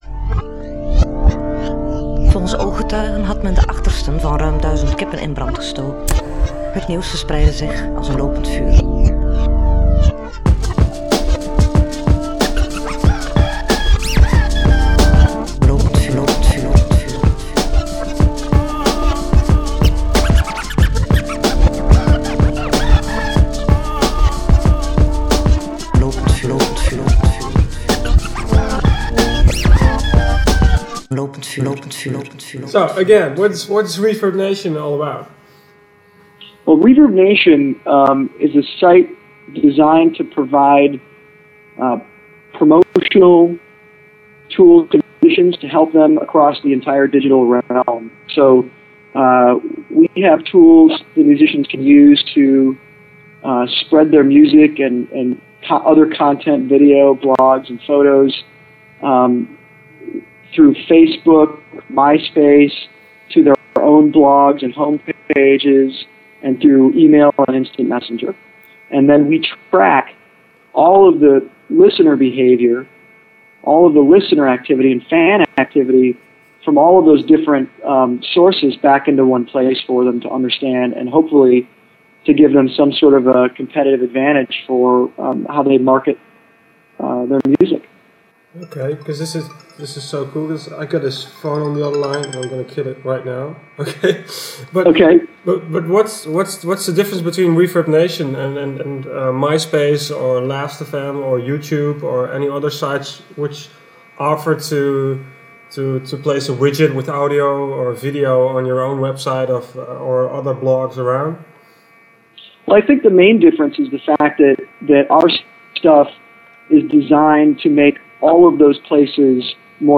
Excuses voor de hickups en het geruis. Helaas zat een betere verbinding via Skype er niet in.